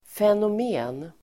Uttal: [fenom'e:n]